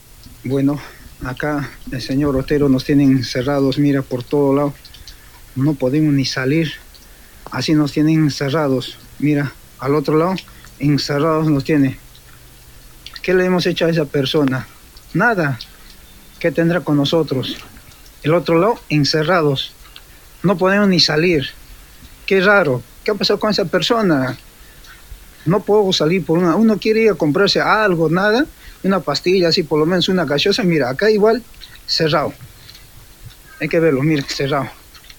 Mientras las imágenes nocturnas hacen foco en cadenas con candados que bloquean las salidas, se escucha el relato de uno de los obreros damnificados narrando la situación: